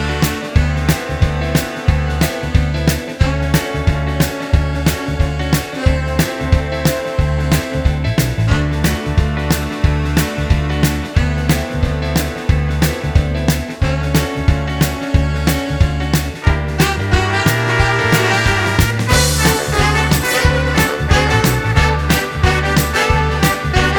Medley Backing Tracks Singalong